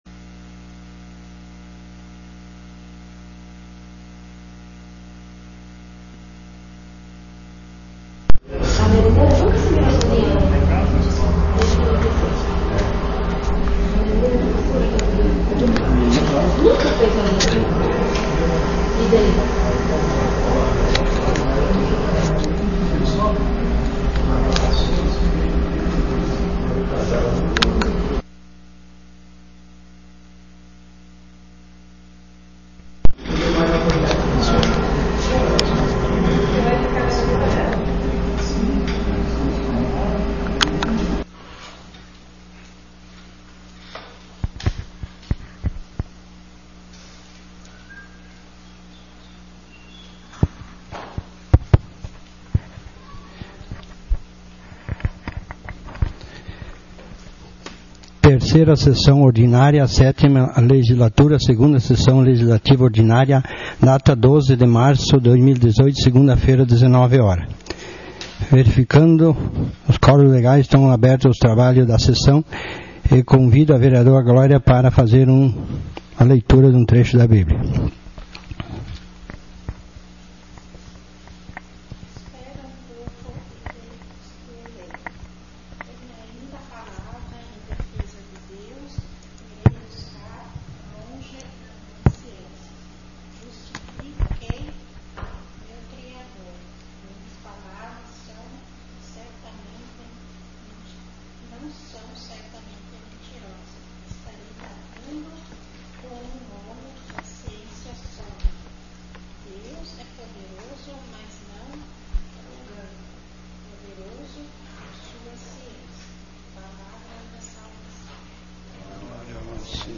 3ª Sessão Ordinária 12.03.18